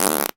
pgs/Assets/Audio/Comedy_Cartoon/fart_squirt_18.wav
fart_squirt_18.wav